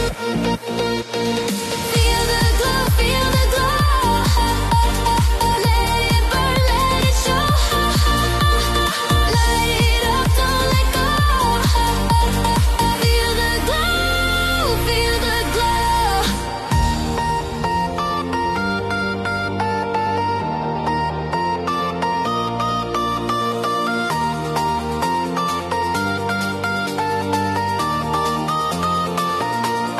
This new EDM track is pure sunshine in a song.